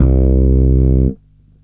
14-C2.wav